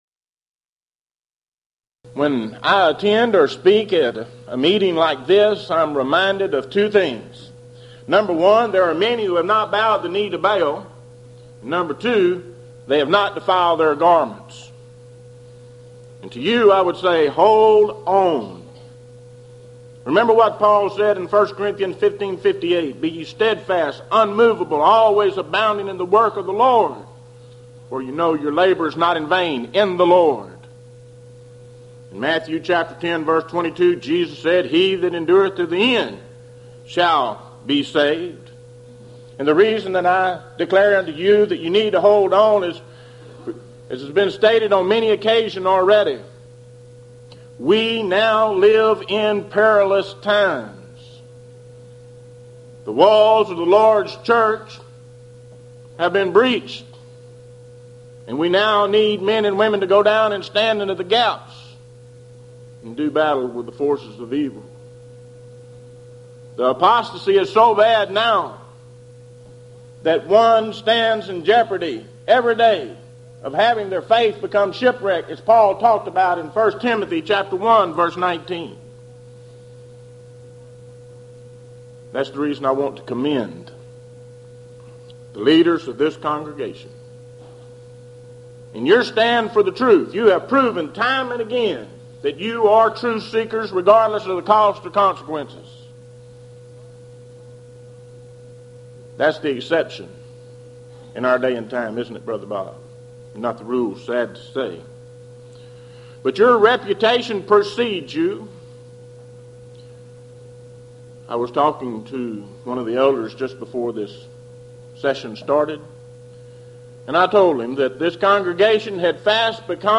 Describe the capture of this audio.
Series: Houston College of the Bible Lectures Event: 1995 HCB Lectures